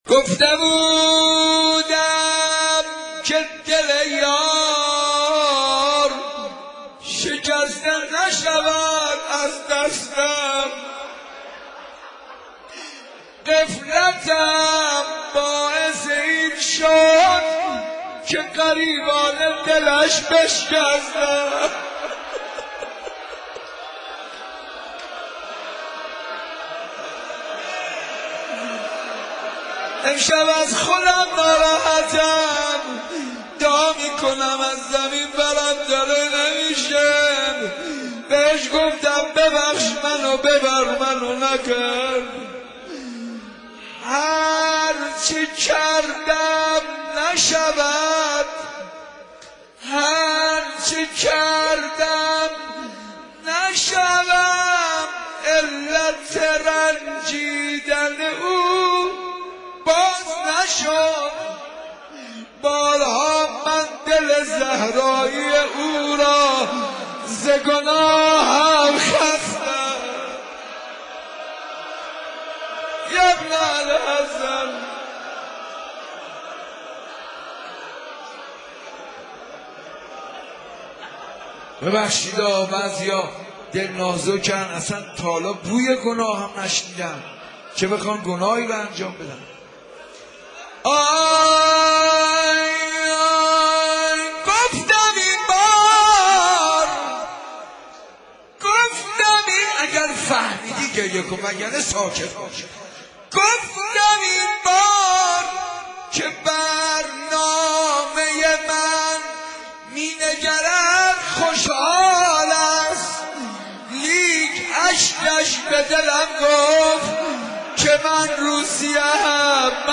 صوت/ مناجاتی شنیدنی با امام زمان(عج)